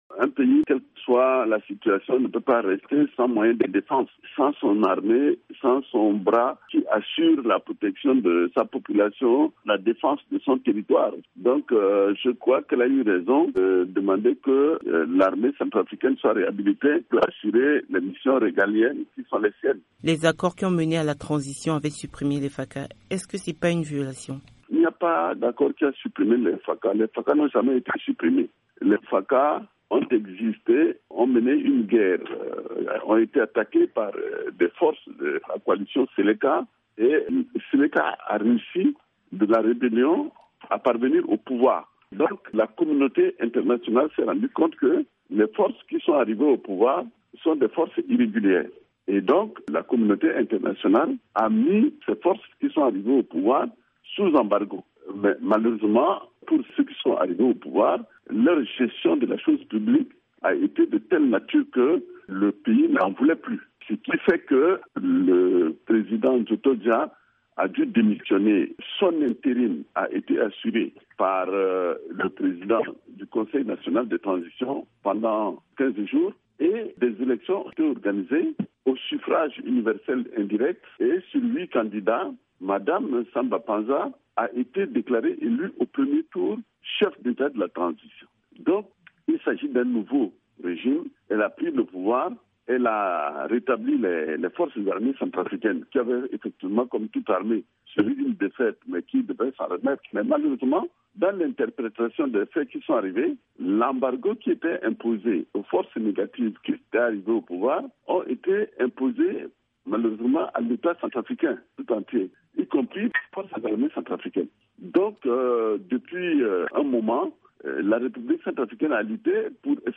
Dans une interview à VOA Afrique, le ministre centrafricain de la Défense Joseph Bindoumi revient sur l’appel à l’ONU de la présidente de la transition centrafricaine à lever l’embargo sur son pays.